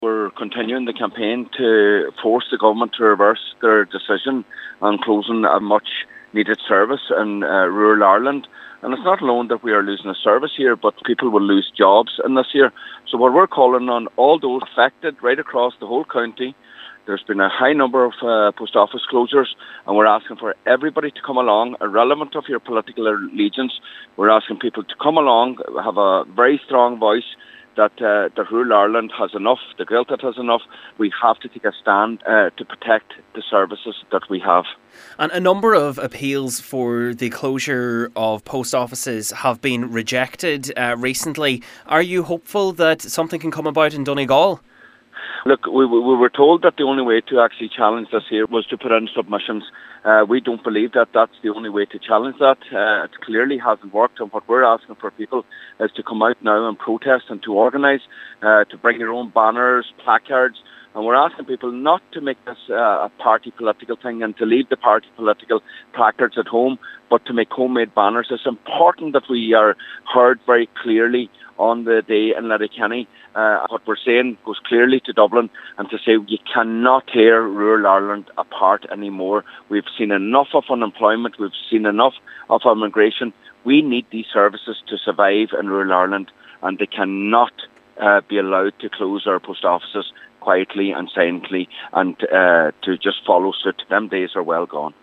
Independent Councillor Mícheál Cholm Mac Giolla Easbuig is one of the organisers of the protest. He says it’s important that the voices of the people of Donegal are heard very clearly on the matter: